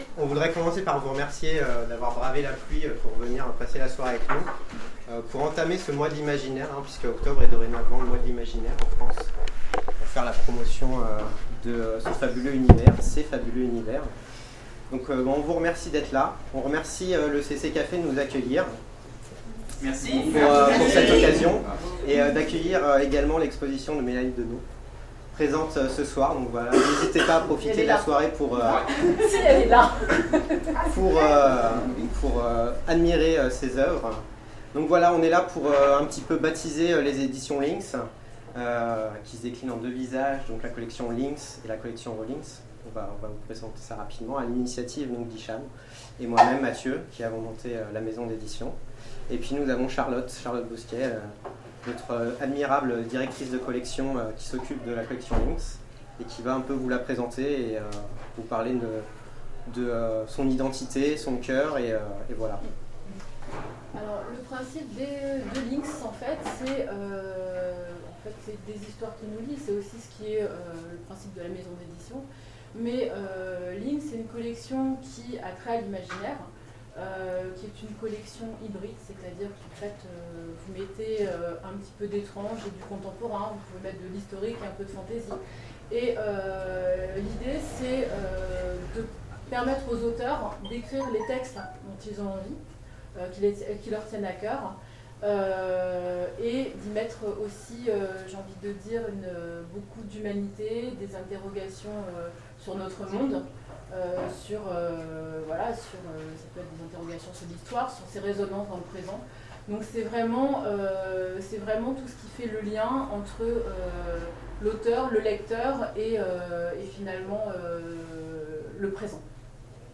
Interview 2017 : Lancement des éditions Lynks